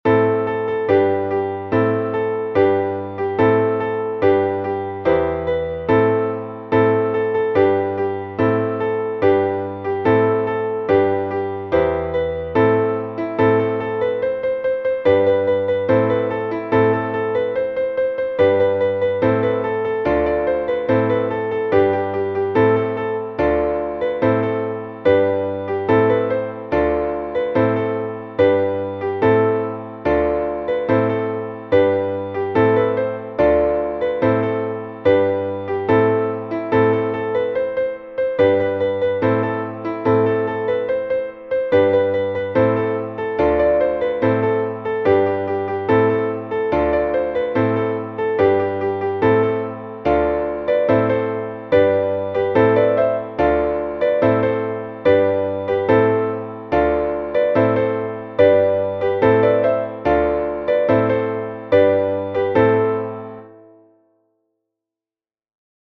μελωδία και συγχορδίες, Amin